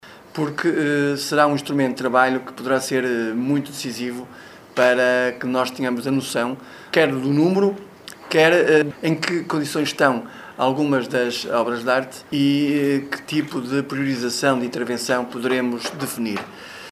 Estes números foram dados a conhecer na apresentação pública do relatório final do “Projeto Reconhecer”, um estudo encomendado, em 2019, pelo Município barcelense e que envolveu a Universidade Lusófona de Humanidades e Tecnologias e o Laboratório Nacional de Engenharia Civil.
Segundo o presidente da Câmara, Mário Constantino, este estudo é importante para que o departamento do Município possa agora avaliar o estado de conservação: